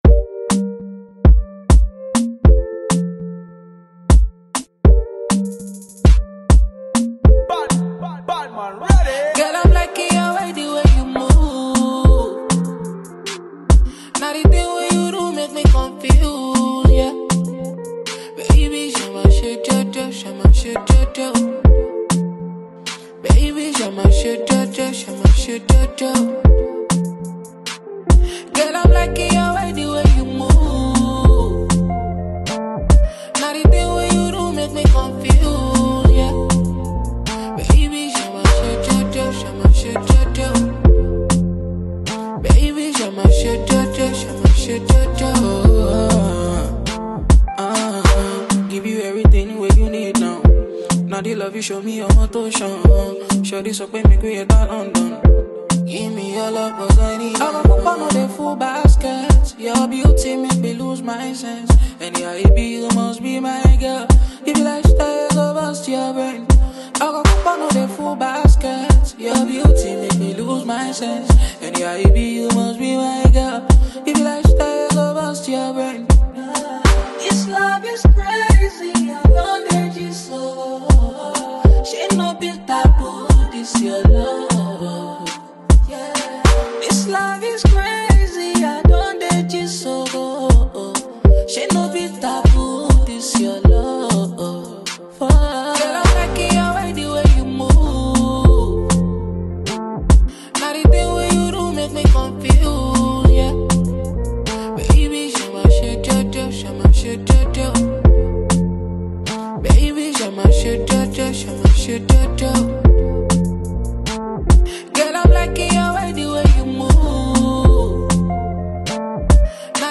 Talented Nigerian singer
With his cool vocals and impeccable songwriting skills